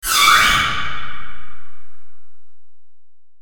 Bijekohuntingcall.mp3